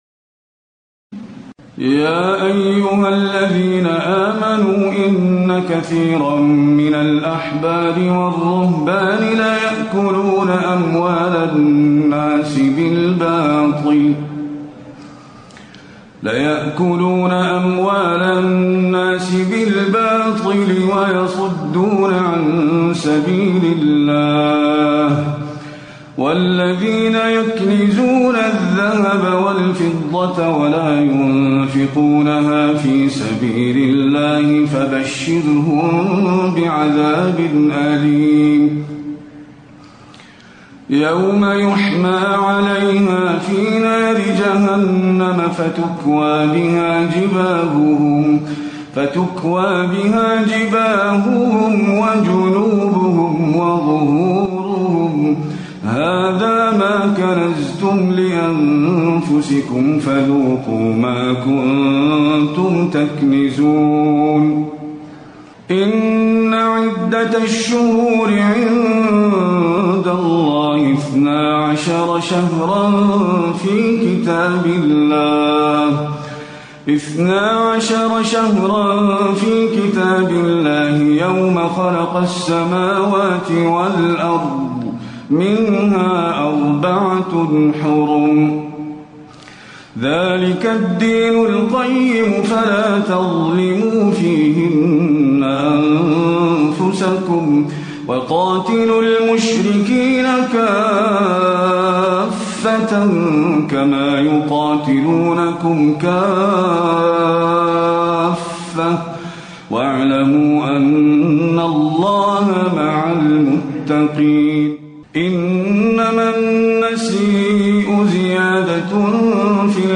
تراويح الليلة العاشرة رمضان 1437هـ من سورة التوبة (34-99) Taraweeh 10 st night Ramadan 1437H from Surah At-Tawba > تراويح الحرم النبوي عام 1437 🕌 > التراويح - تلاوات الحرمين